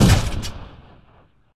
Gun_Turret2.ogg